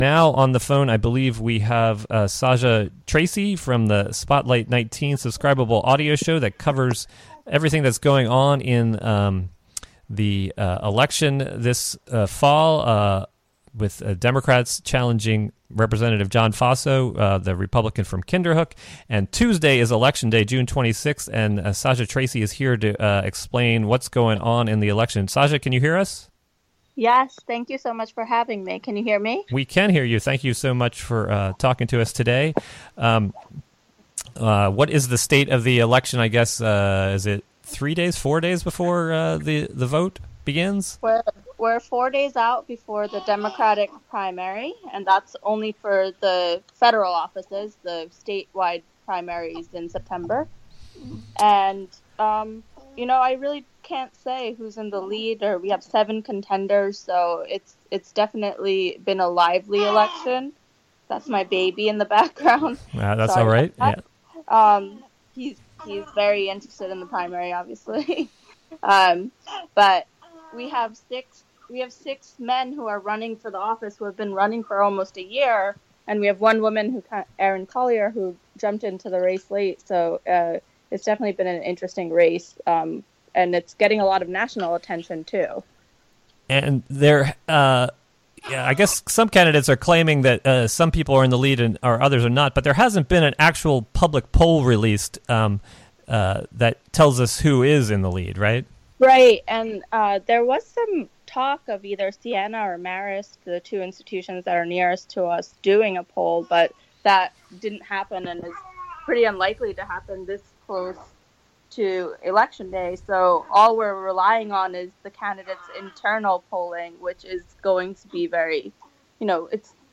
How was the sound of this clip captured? A short portion of the audio near the beginning is missing due to technical reasons.